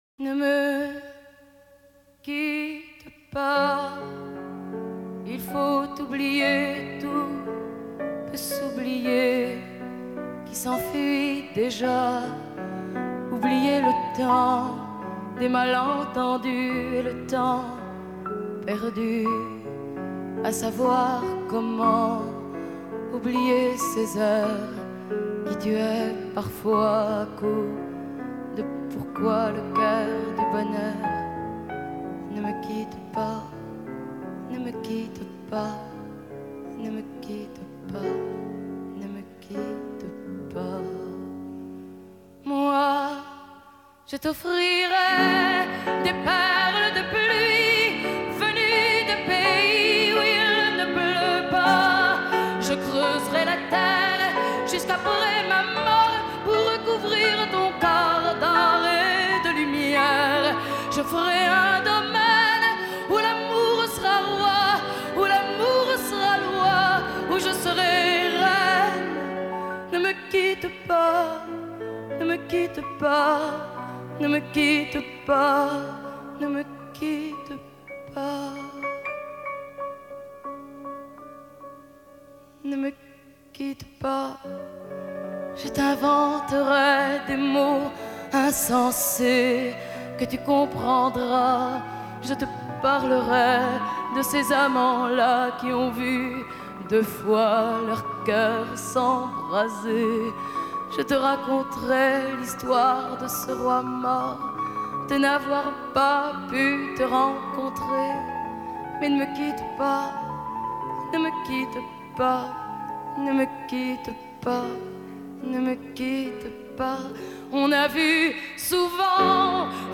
Reprise